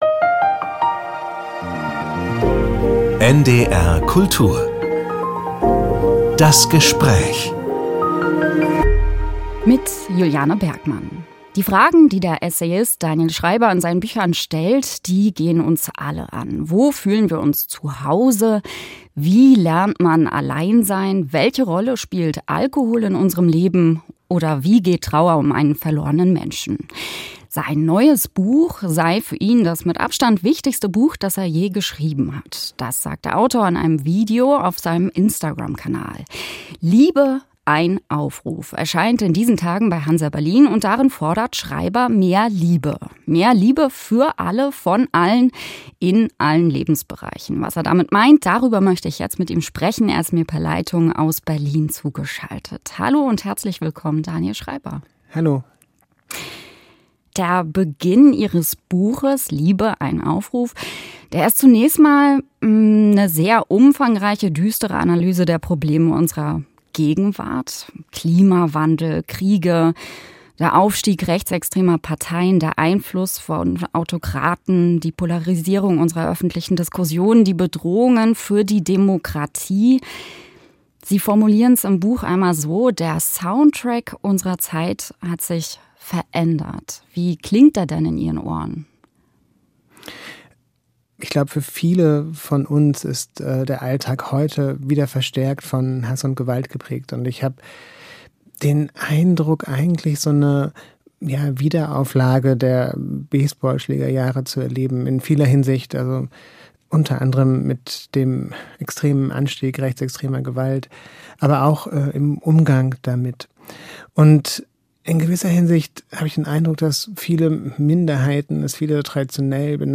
Nur Liebe kann uns noch retten: Autor Daniel Schreiber im Gespräch ~ NDR Kultur - Das Gespräch Podcast